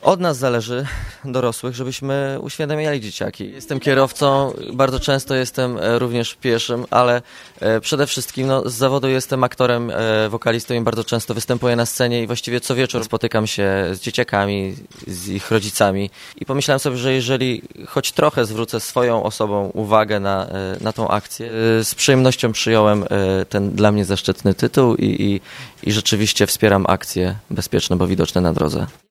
W filmikach wystąpili Paulina Holtz, Karolina Malinowska, Omenaa Mensah oraz Artur Chamski, który mówi dlaczego postanowił wspierać akcję policji.